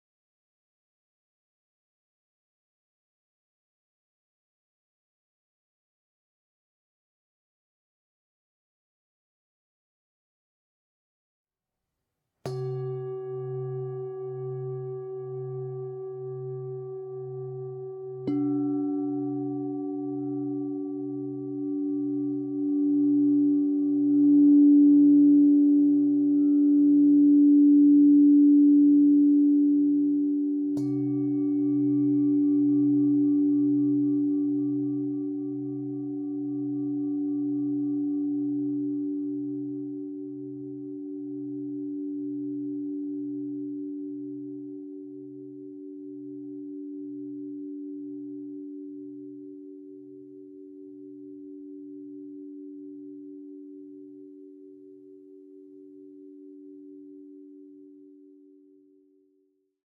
Effortlessly switch between metallic / Tibetan singing bowls and crystal singing bowls, eliminating the need for constant mallet changes.